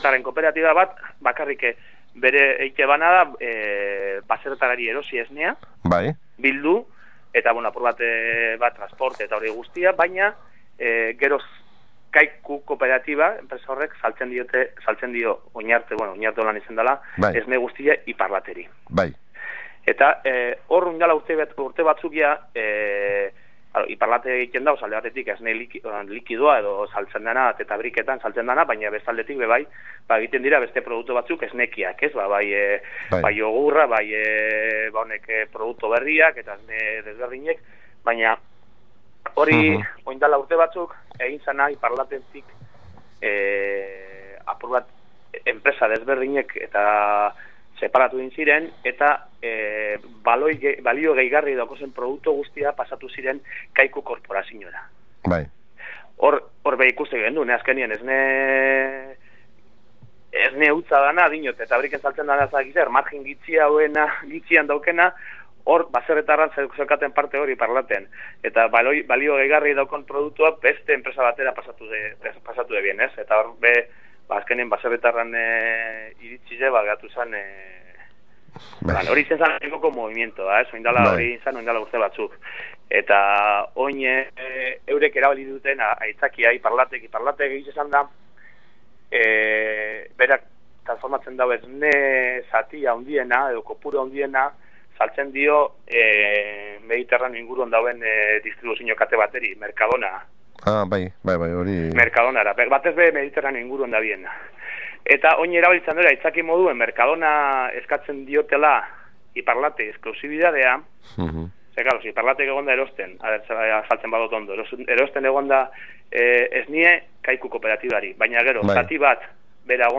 solasaldia
telefonoa bitarteko dela.